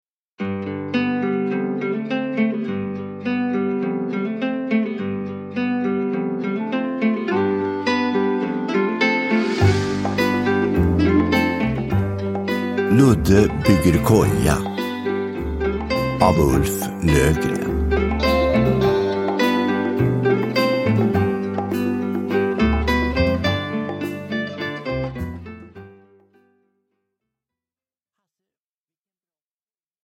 Ludde bygger koja – Ljudbok – Laddas ner